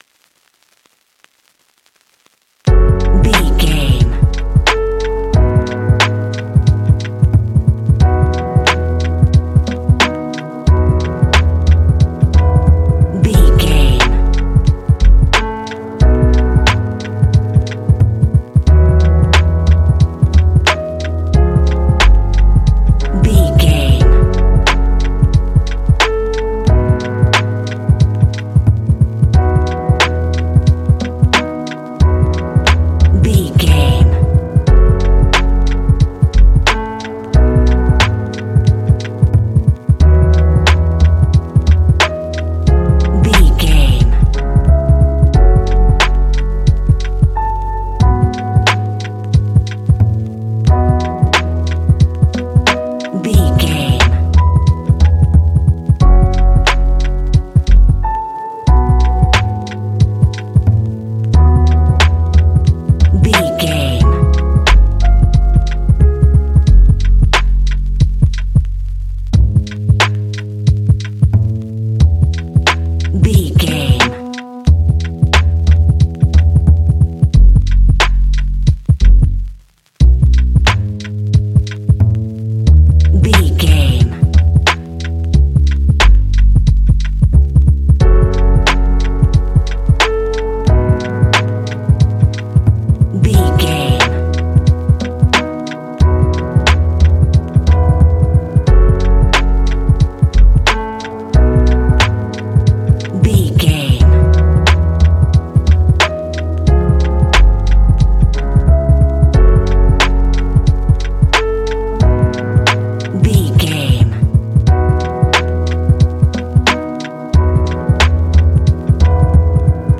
Ionian/Major
C♯
chilled
laid back
Lounge
sparse
new age
chilled electronica
ambient
atmospheric
morphing